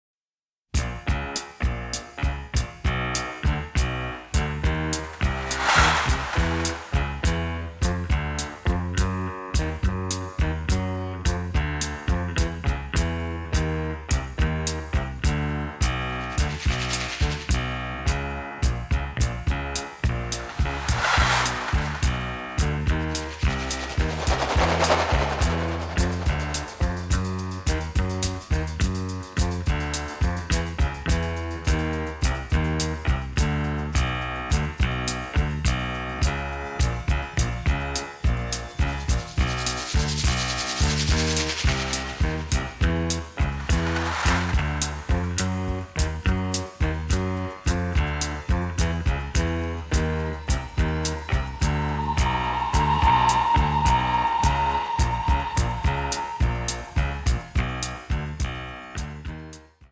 propulsive action theme